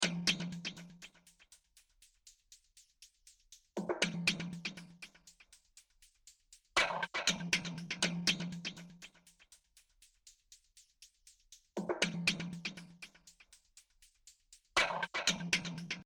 Вот примерно такие лупы в каких библиотеках есть? Область применения- так называемая яма...где пэд например играет и в дали что-то ритмическое нужно чтоб ритм пульсация была.